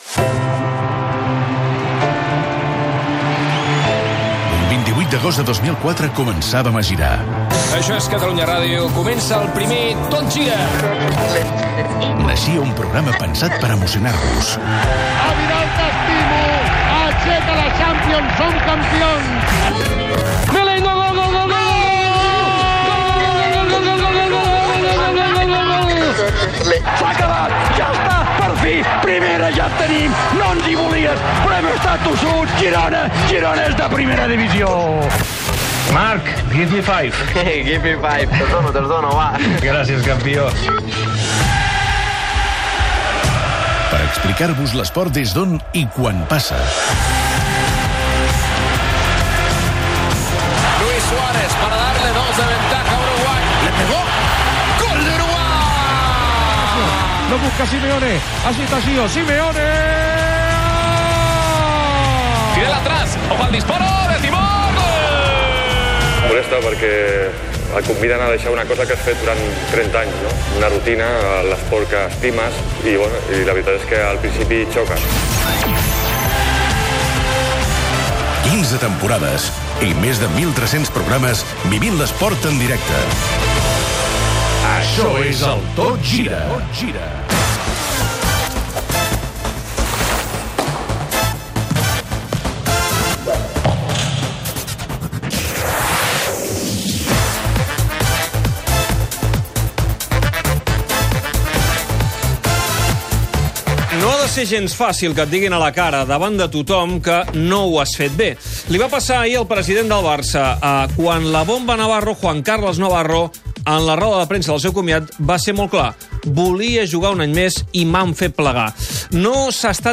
Espai 1354. Careta del programa amb fragments retrospectius, comiat del jugador de bàsquet Juan Carlos Navarro, indicatiu del programa, repàs als resultats dels partits en joc, agenda esportiva, resum informatiu, indicatiu, les xarxes socials, seguiment de la primera semifinal de la Lliga catalana de bàsquet, Andorra-Joventut, des de Lleida
Esportiu